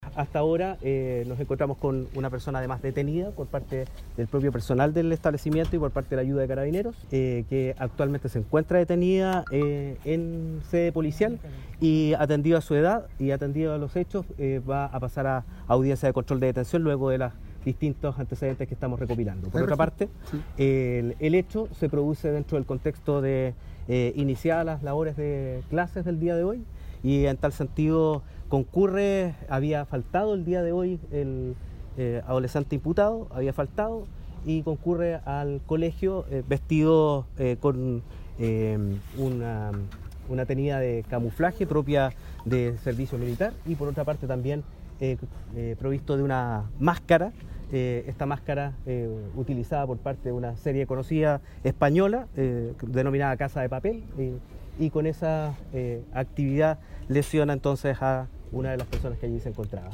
27-FISCAL-MIN-PUB-2.mp3